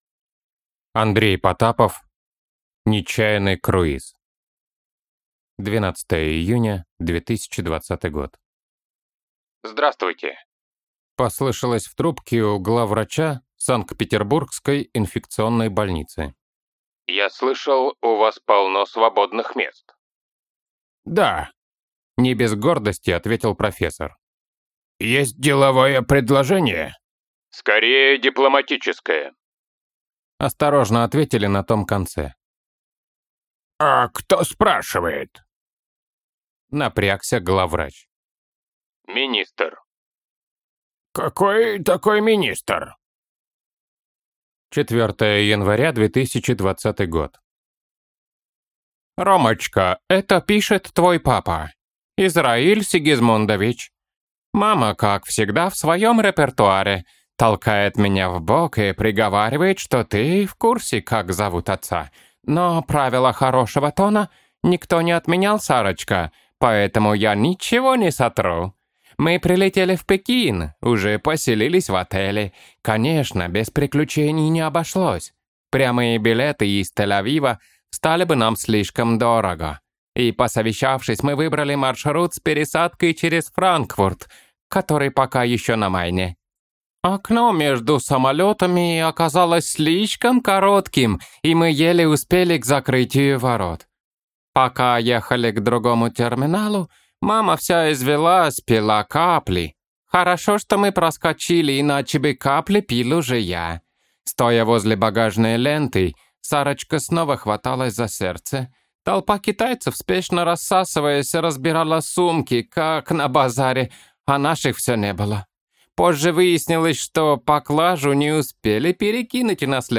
Аудиокнига Нечаянный круиз | Библиотека аудиокниг